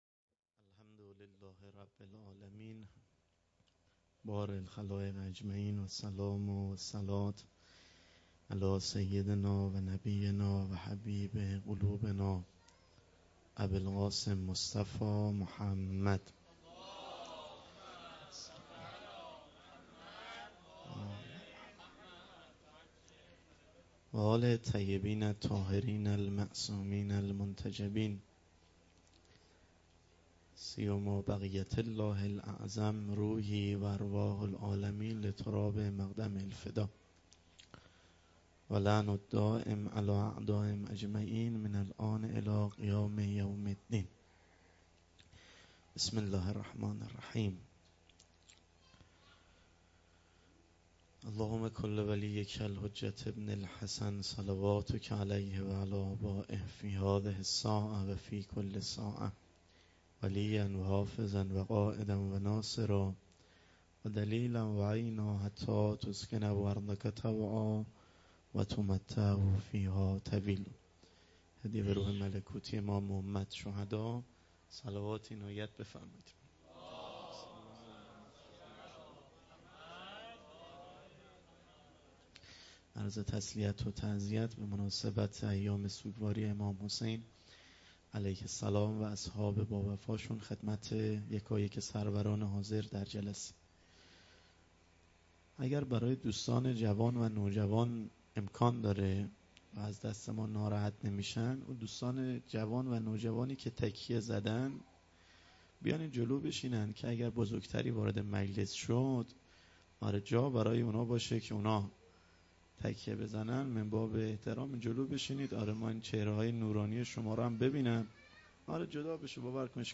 هیئت محبین انصار المهدی(عج)_محرم98